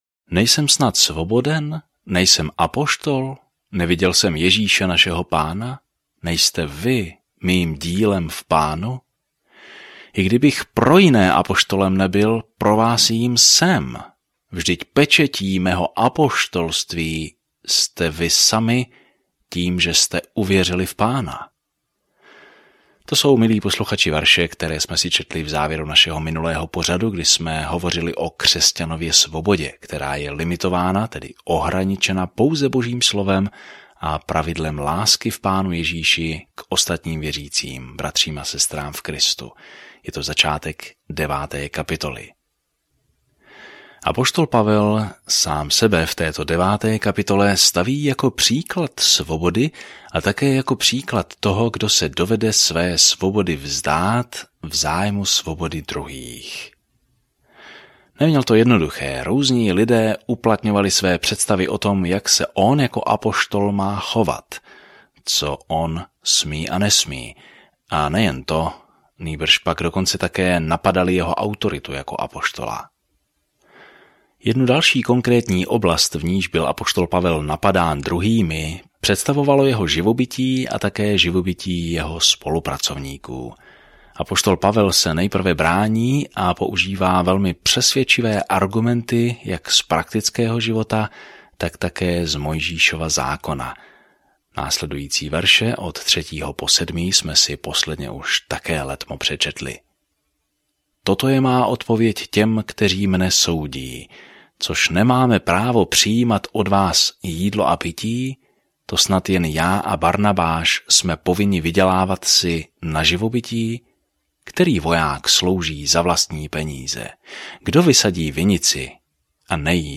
Písmo 1 Korintským 9 Den 17 Začít tento plán Den 19 O tomto plánu "Jak by měl žít křesťan?" Je tématem, kterým se zabývá první dopis Korinťanům, praktickou péči a nápravu problémům, kterým mladí křesťané čelí? Denně procházejte 1. listem Korinťanům, zatímco budete poslouchat audiostudii a číst vybrané verše z Božího slova.